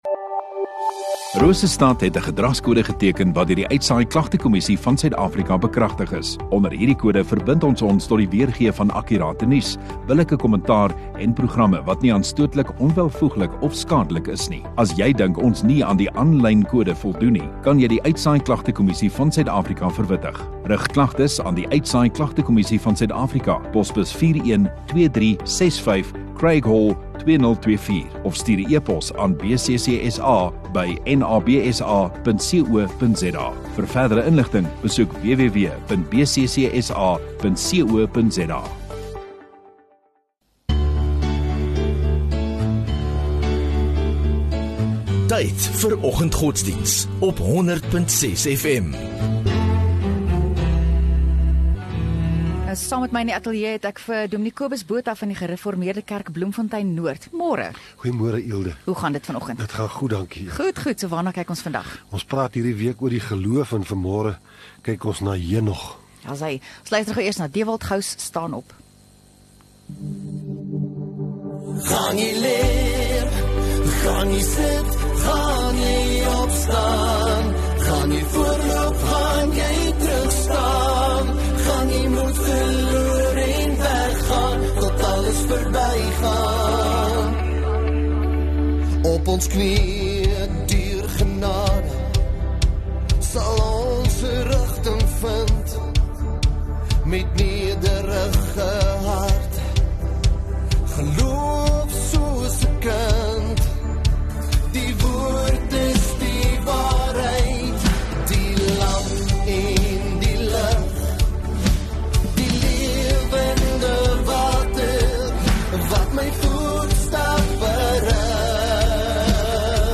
14 Aug Woensdag Oggenddiens